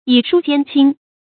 以疏間親 注音： ㄧˇ ㄕㄨ ㄐㄧㄢˋ ㄑㄧㄣ 讀音讀法： 意思解釋： 指關系疏遠的人離間關系親近的人。